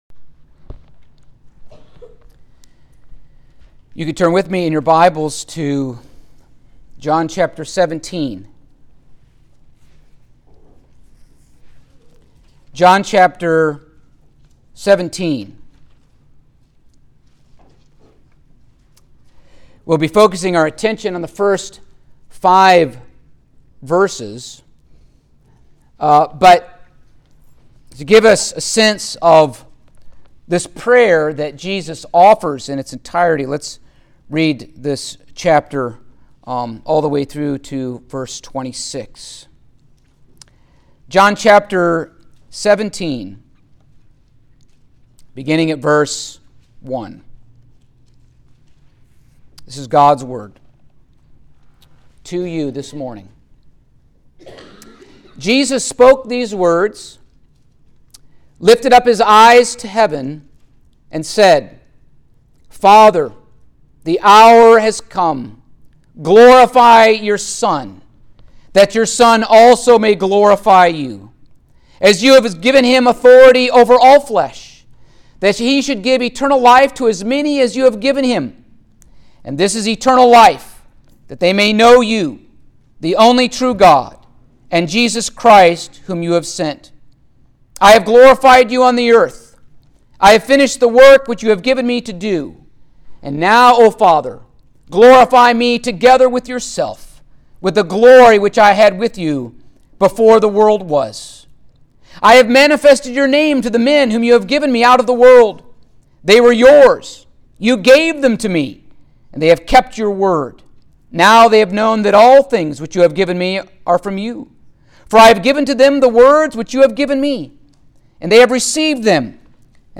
Passage: John 17:1-5 Service Type: Sunday Morning